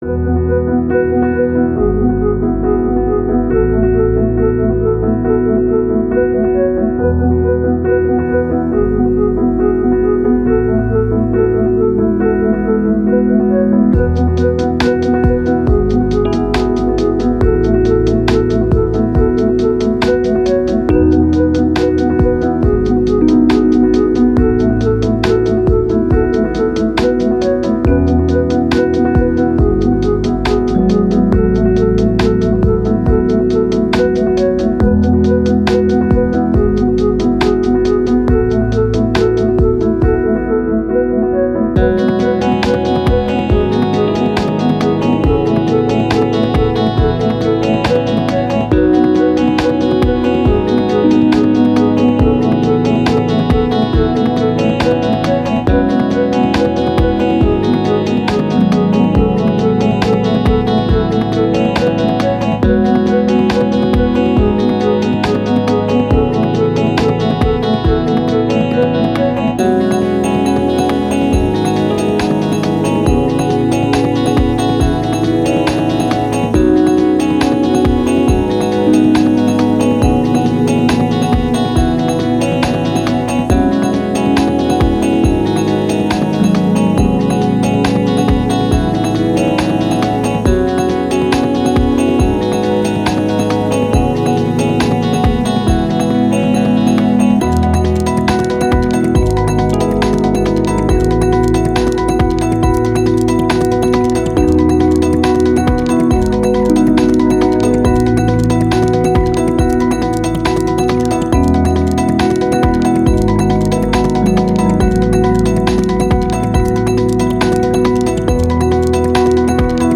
Ambient, Downtempo, Soundtrack, Thoughtful, Story